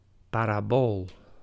Ääntäminen
IPA: /ˌparaˈboːl/